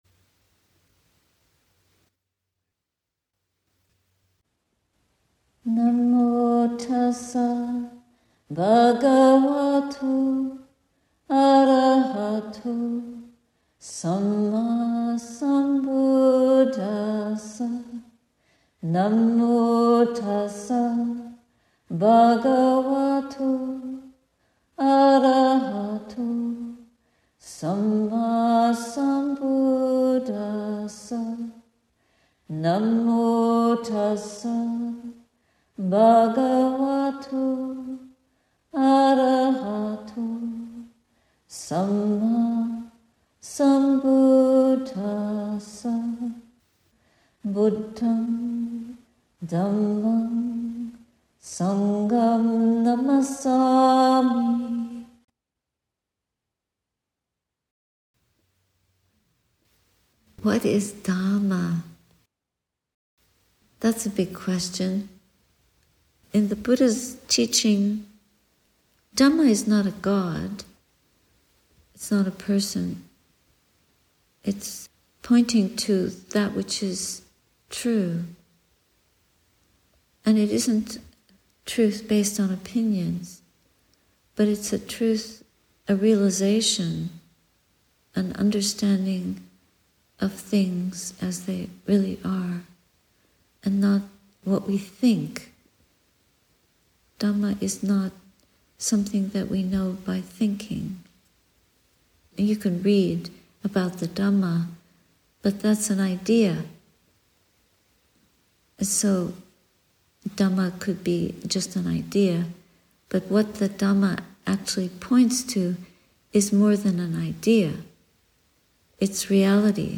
Sati Saraniya Sunday talk, Sept 1, 2024